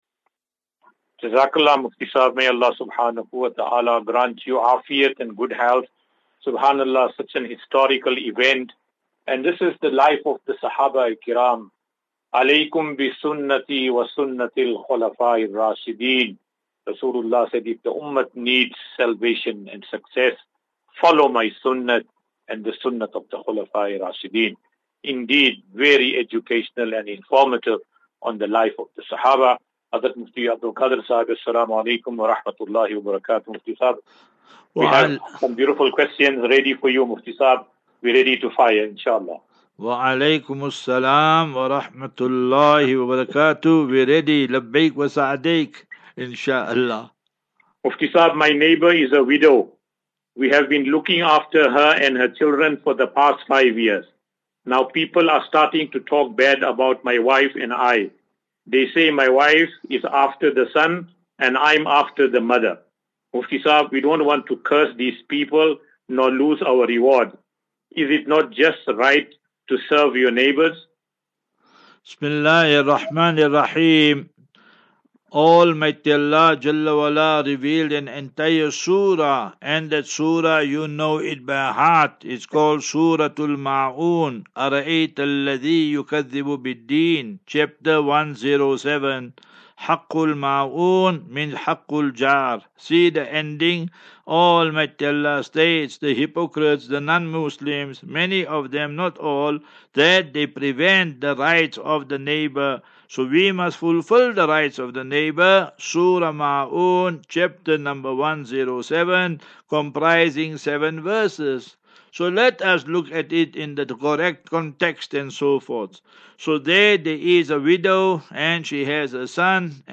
As Safinatu Ilal Jannah Naseeha and Q and A 13 Mar 13 March 2024.